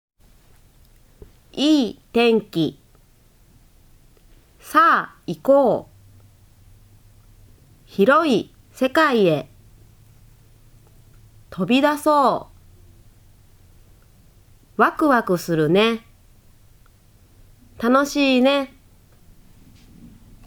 １年 国語の音読について
きょうは せんせいたちは こくごの きょうかしょを よんでみました。みんなも がっこうが おやすみの ときに まねして よんでみてくださいね。